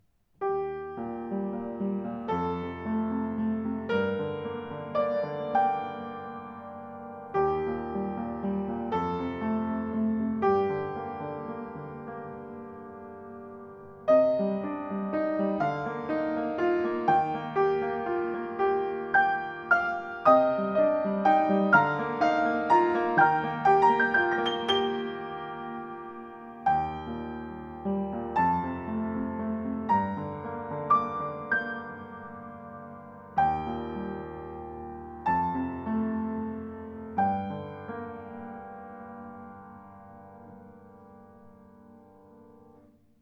Klavier Yamaha U1 weiss
demnächst verfügbar: Weiß poliert mit brillantem, ausdrucksvollem Klang.
Klangbeispiele eines baugleichen Klavieres von uns: